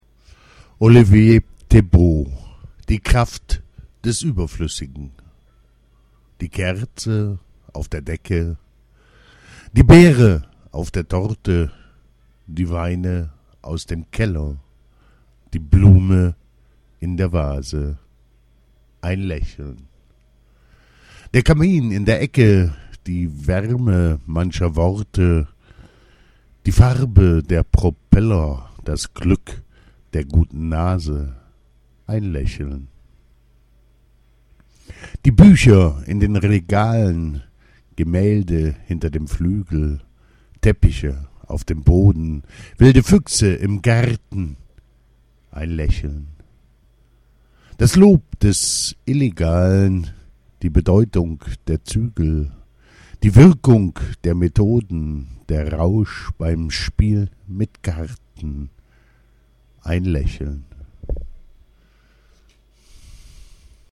Lesungen: